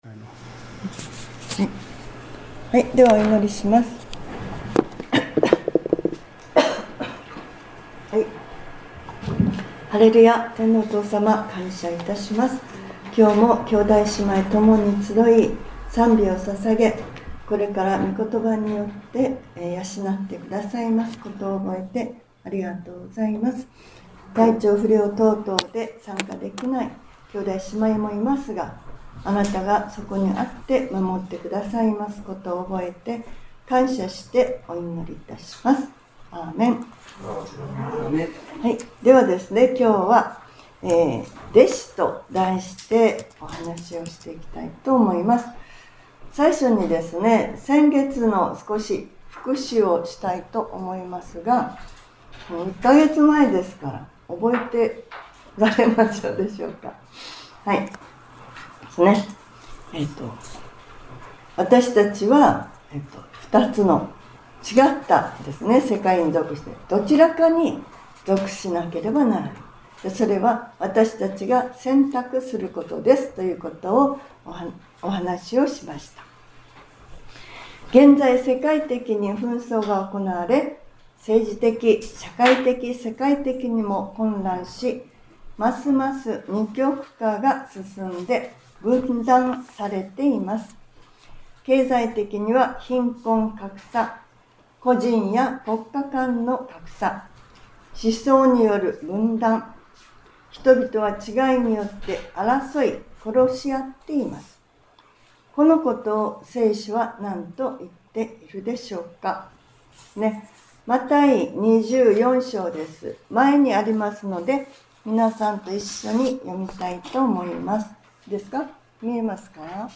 2025年10月19日（日）礼拝説教『 キリストの弟子 』